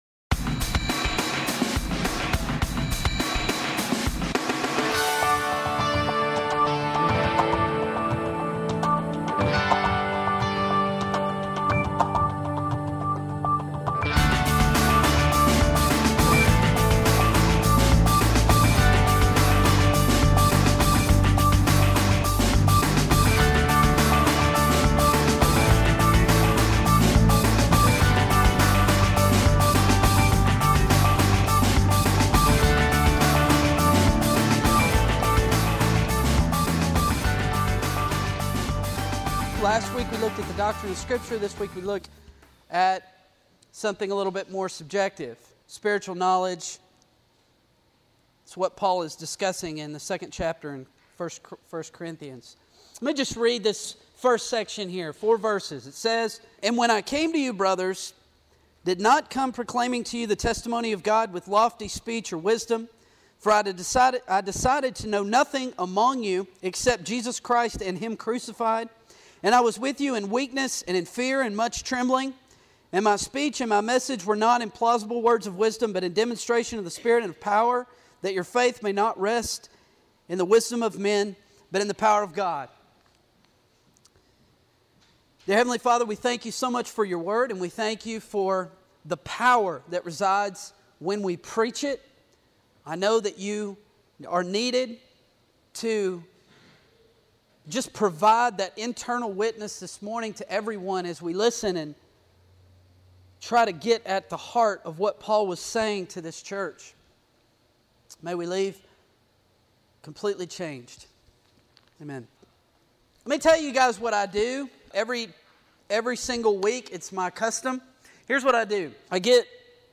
A message from the series "Fused."